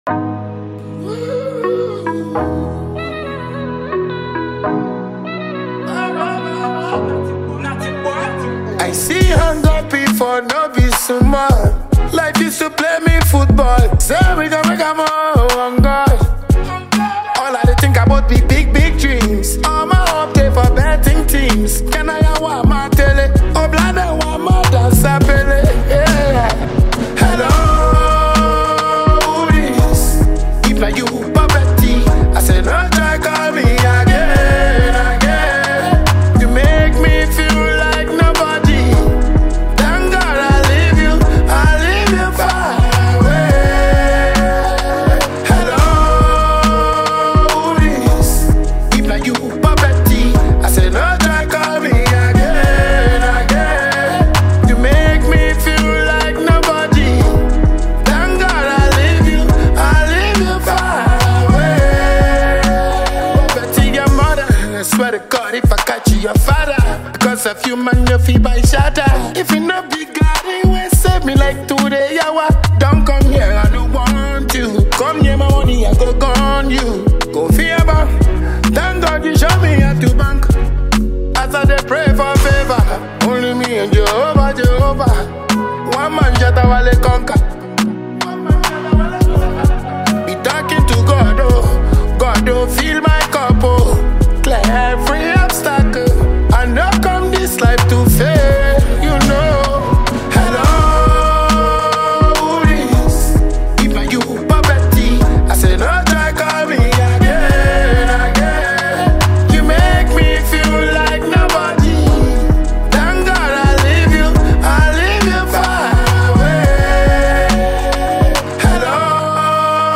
a top-notch self-acclaimed African Dancehall King